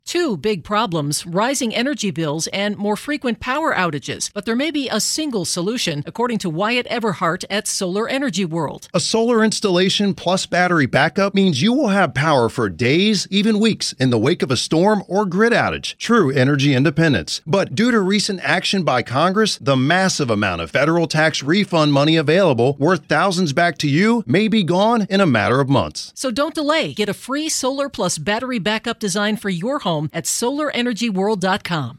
is answering some of the most pressing questions about shifting to solar power in 2025 in the new 5-part WTOP interview series below.